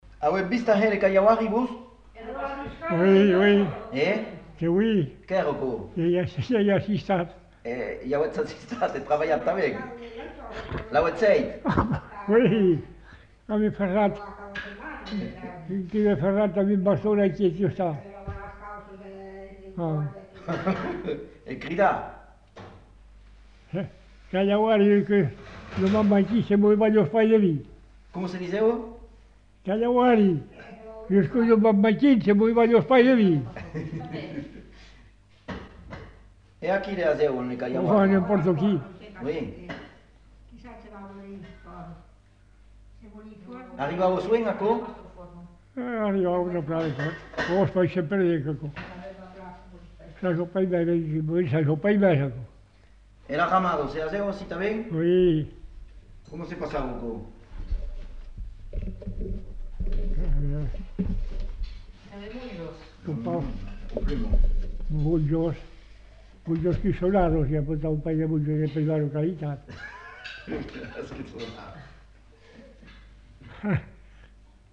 Genre : forme brève
Effectif : 1
Type de voix : voix d'homme
Production du son : parlé
Classification : locution populaire